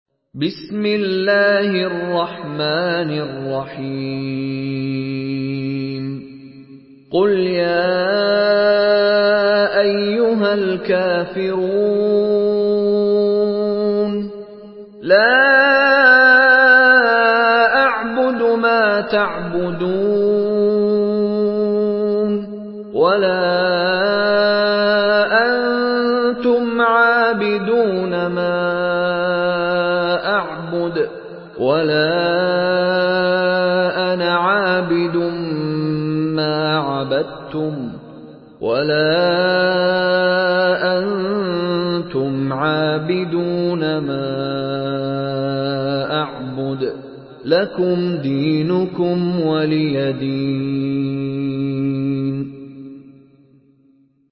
سورة الكافرون MP3 بصوت مشاري راشد العفاسي برواية حفص
مرتل حفص عن عاصم